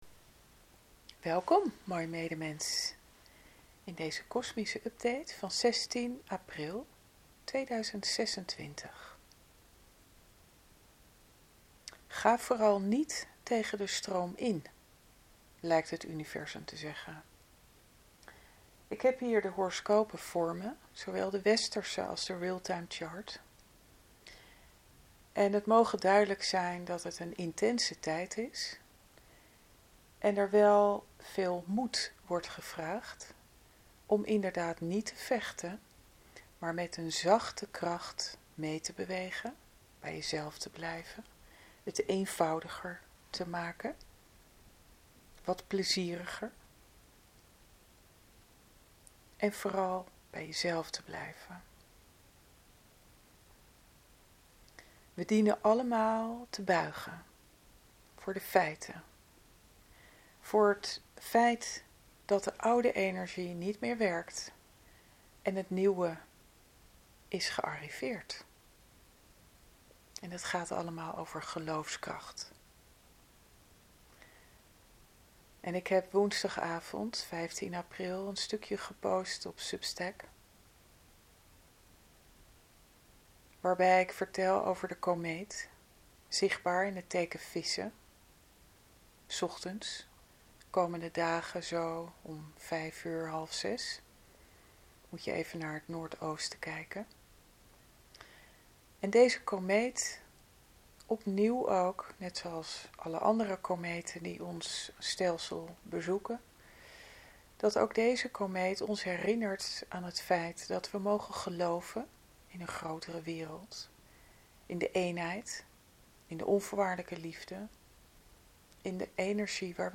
Ik wens dat mijn ingesproken kosmische update van bijna een half uur, jouw hart raakt en bemoedigt.